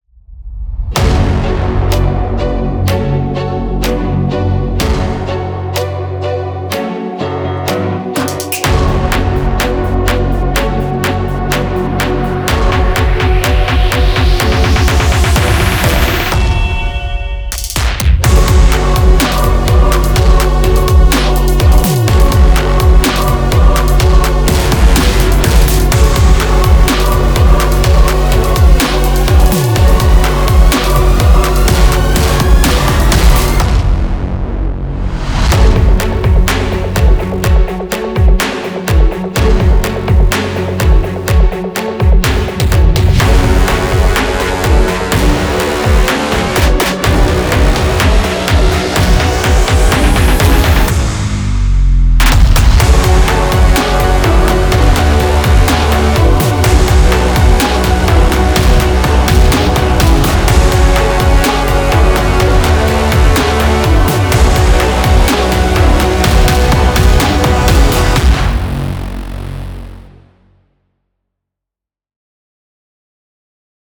Audio demos
Epic sounds.
Dark cinematic drum kits